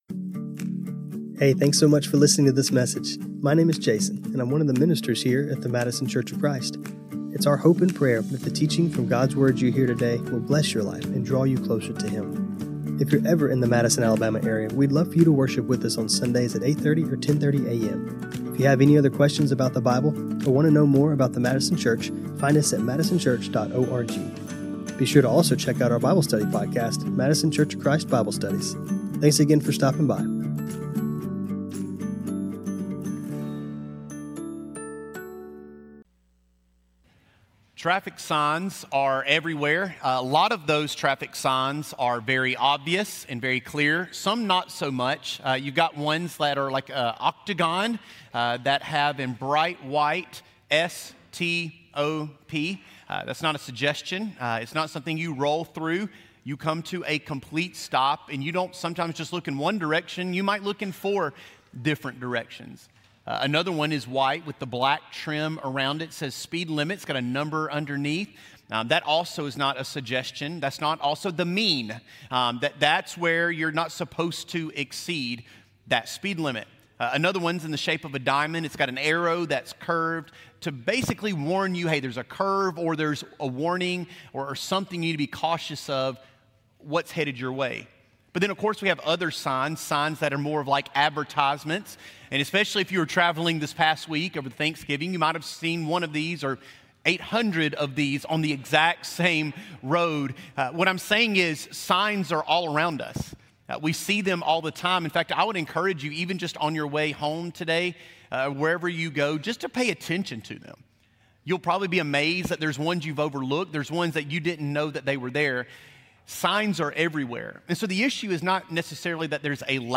This sermon was recorded on Dec 1, 2024.